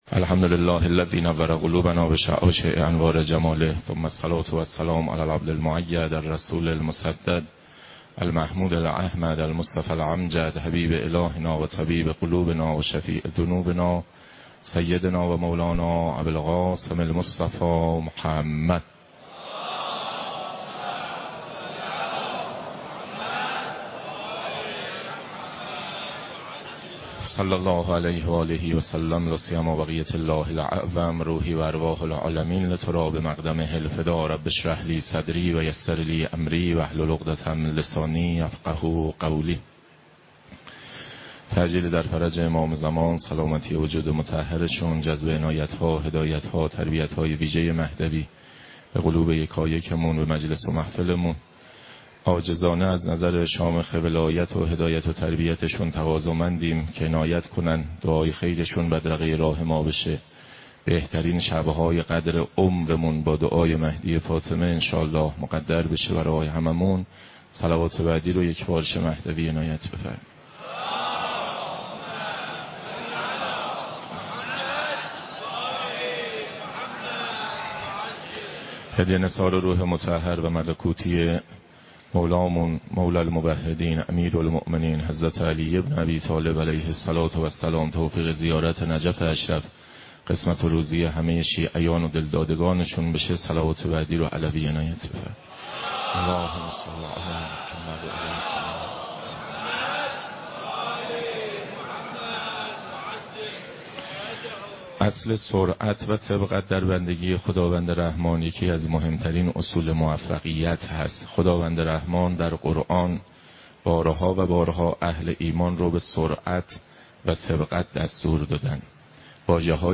حجم: 9.77 MB | تاریخ: 18 /رمضان/ 1439 | مکان: مسجد مقدس جمکران